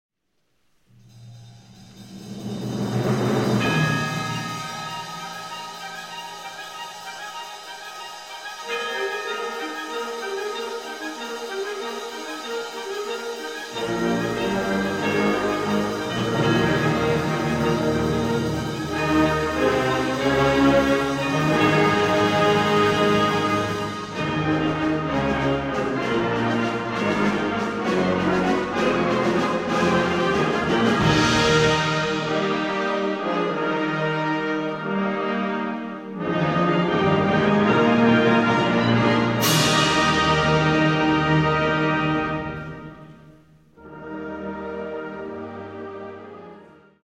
Gattung: Advents- und Weihnachtslied, Choral
Besetzung: Blasorchester